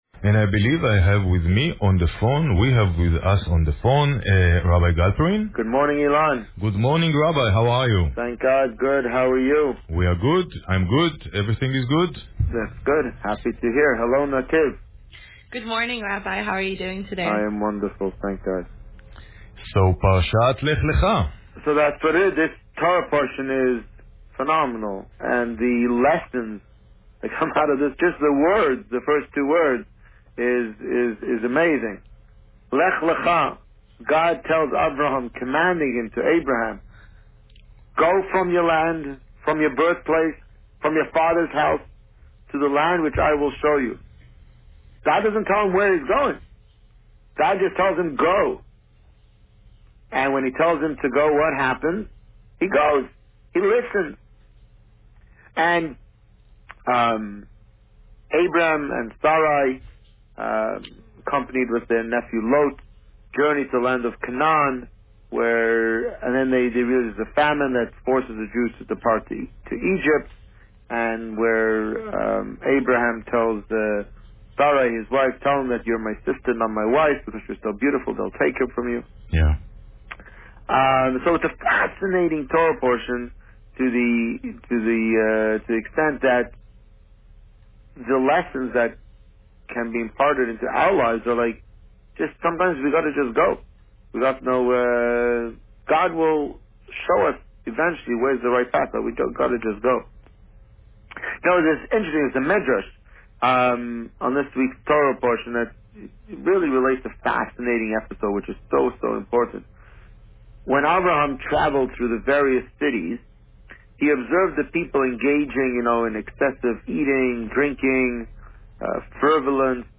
This week, the Rabbi spoke about Parsha Lech Lecha. Listen to the interview here.